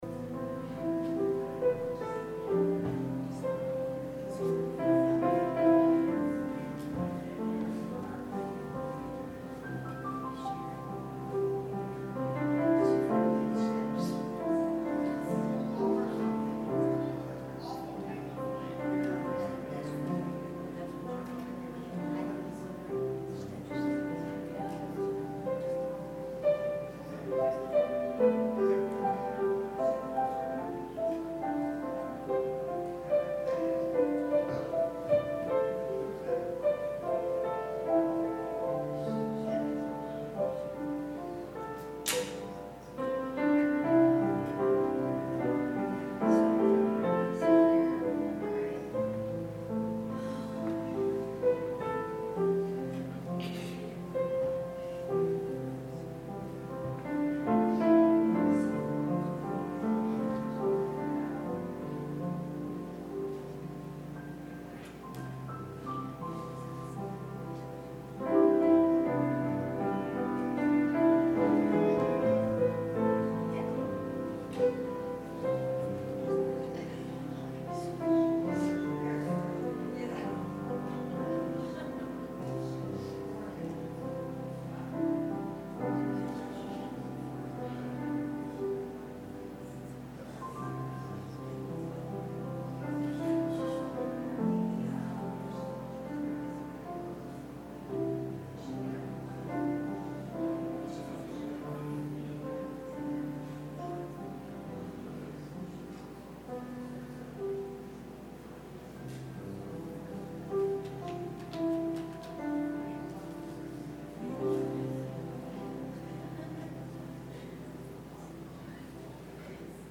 Sermon – September 16, 2018